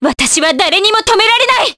Scarlet-Vox_Skill1_Jp.wav